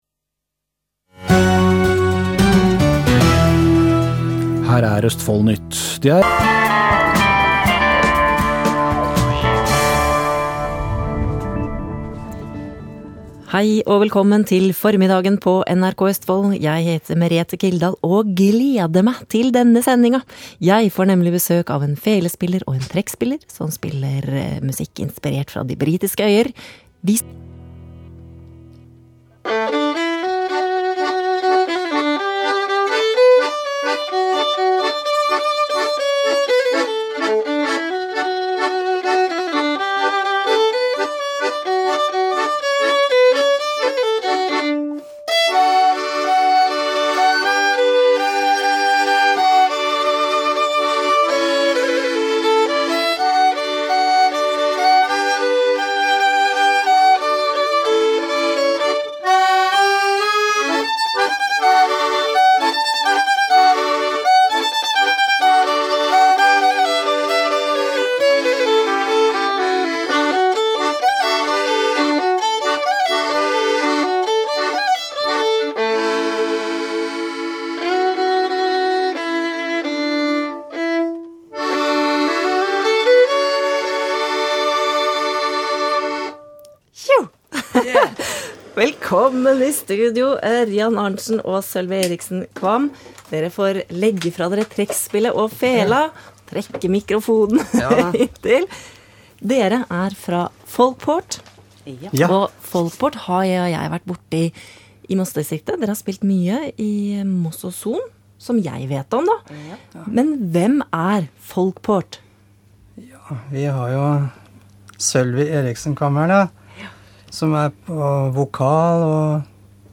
i studio i NRK Østfold
intervju